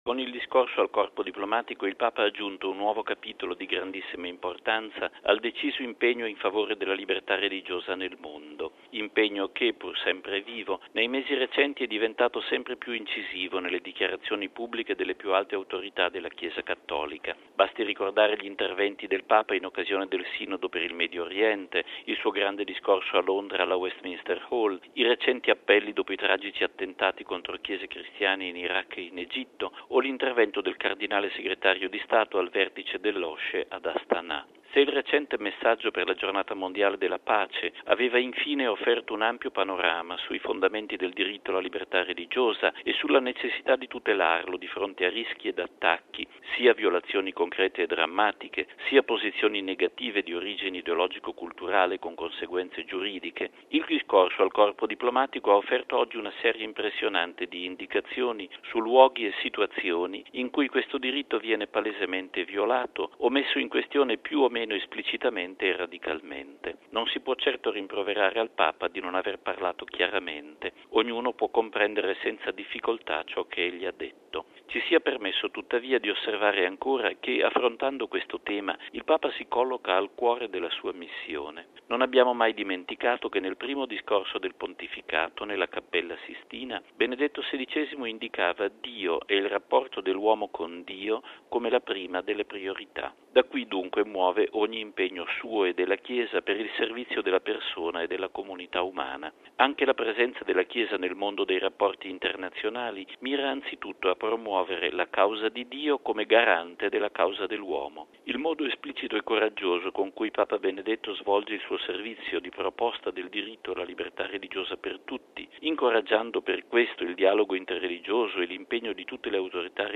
◊   Sul discorso del Papa al Corpo Diplomatico ascoltiamo la riflessione del direttore della Sala Stampa vaticana, padre Federico Lombardi: